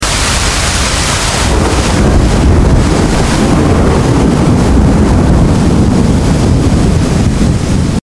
Suara Hujan dan aliran air, Relaksasi bikin suasana tenang di alam Pedesaan